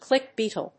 アクセントclíck bèetle